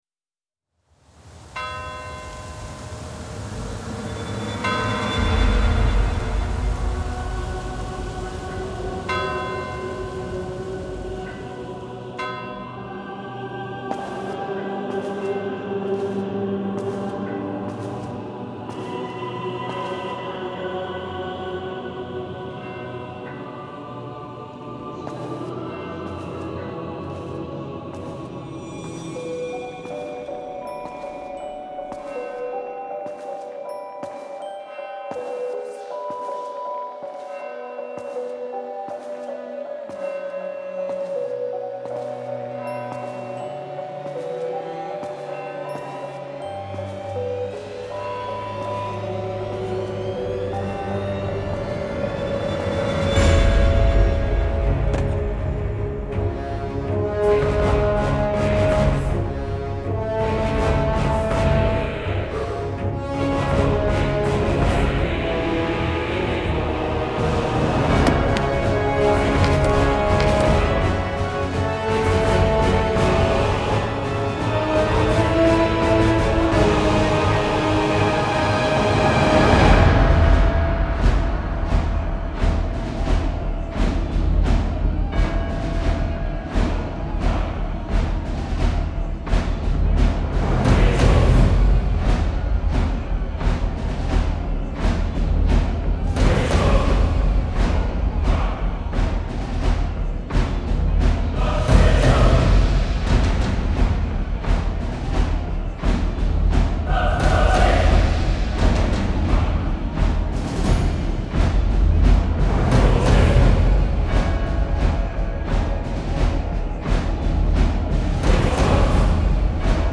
Genre : Pop, Rock, Metal